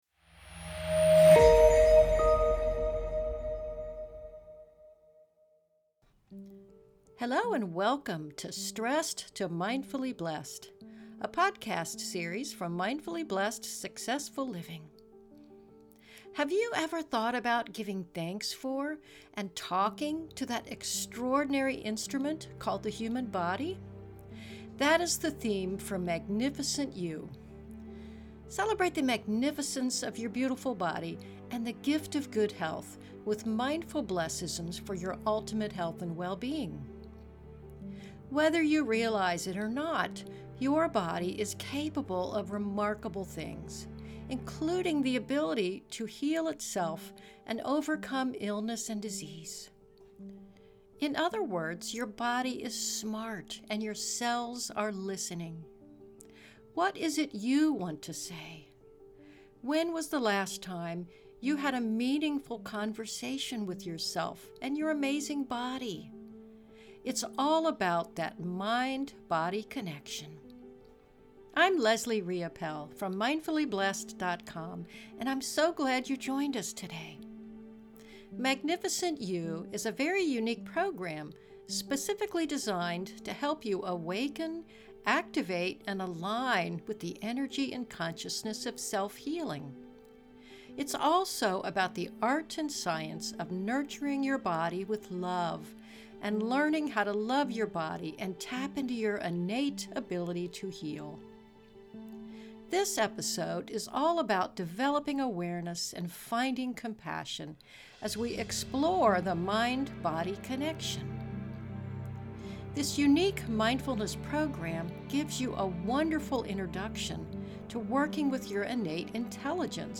This session contains a body scan mindfulness session that also includes all of the lovely BLESSisms from week 1. Have you ever thought about giving thanks for and talking to that extraordinary instrument called the human body?